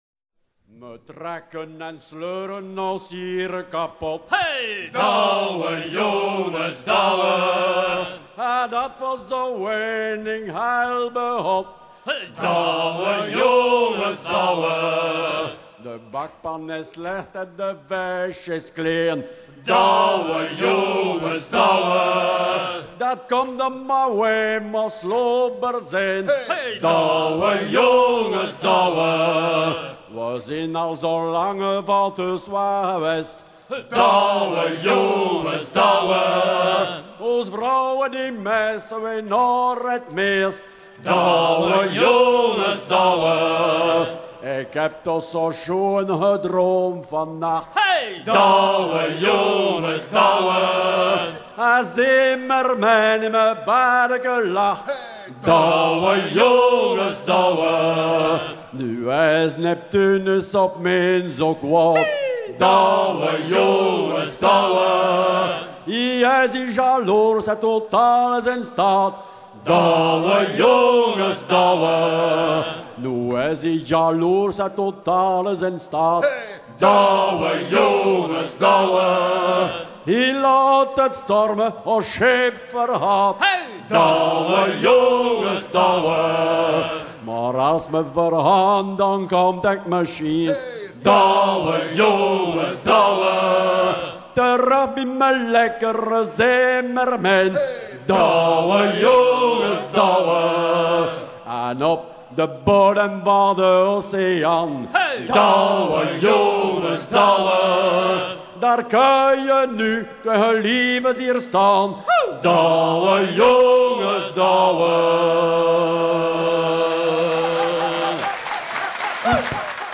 chant en flamand qui semble provenir de la région d'Anvers
maritimes